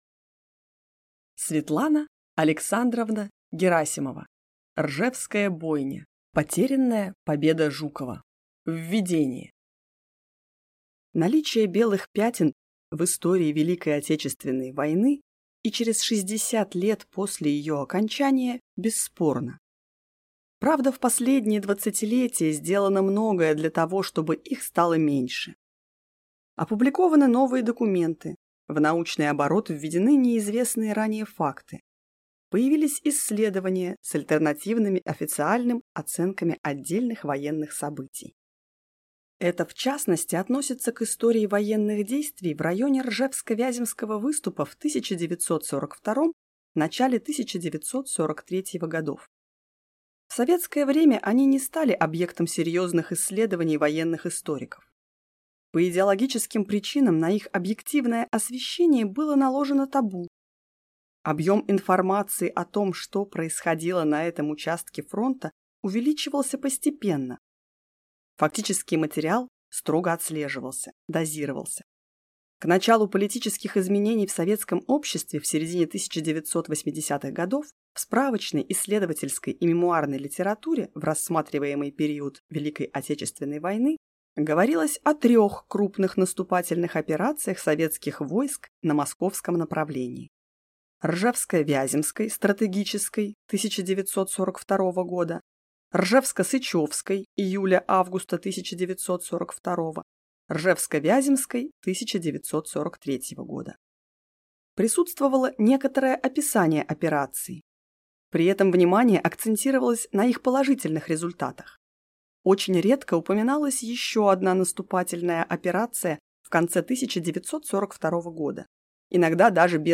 Аудиокнига Ржевская бойня. Потерянная победа Жукова | Библиотека аудиокниг